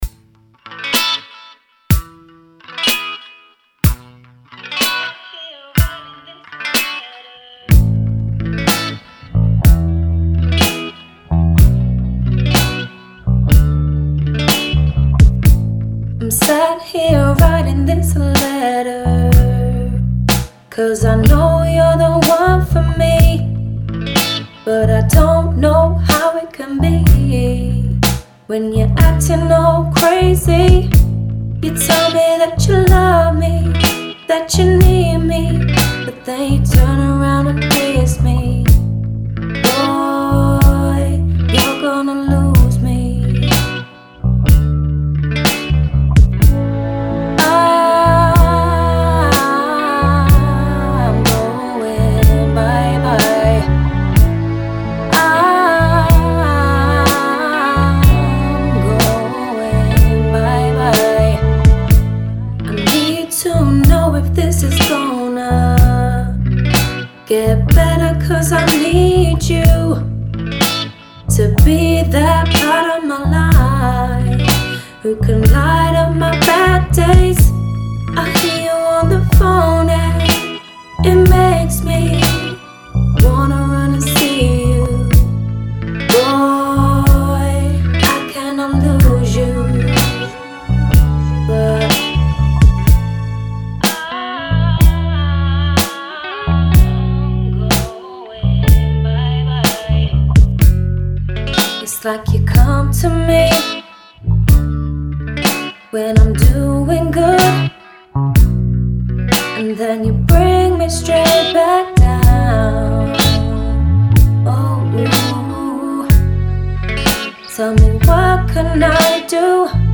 So, I decided to re mix this song.
And also since it's a pretty simple song, is there enough little bits happening to keep your attention? I've only just finished the 3rd mix of this today so I wouldn't say it's completely done yet. And I've just got a buss compressor on and limiter to make it loud enough, so I wouldn't call it mastered either.